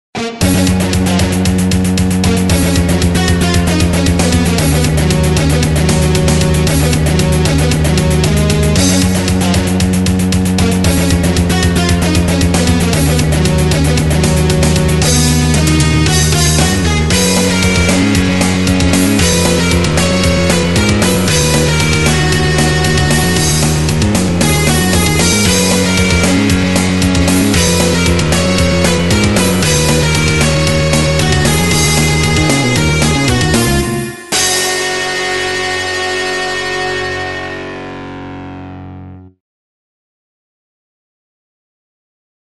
基本、音楽はＭＩＤＩで打ち込んでＳＯＬ２（VST対応のDAWソフトウェア）
そしてこれがＳＯＬ２でエフェクトを加えた編集後。